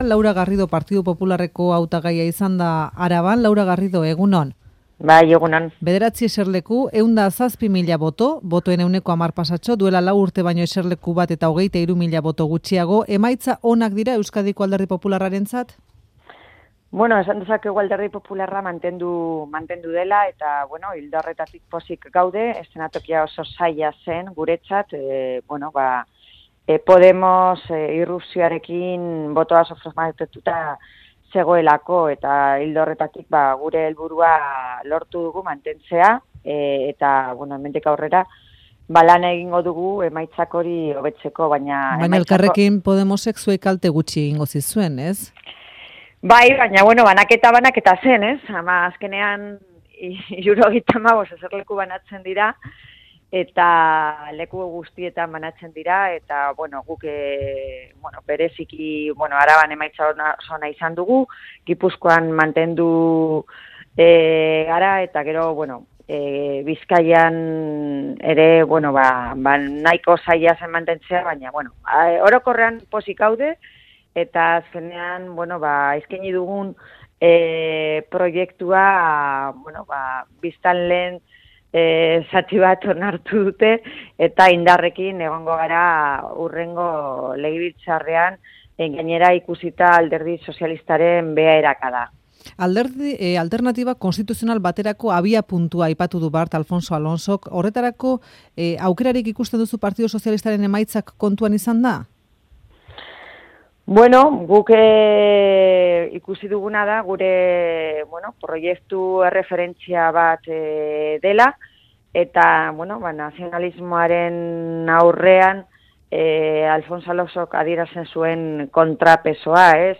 Audioa: Laura Garrido Ppren hautagaa irailaren 25eko legebiltzarrerako hauteskundeen emaitzez aritu da Euskadi irratiko Faktorian.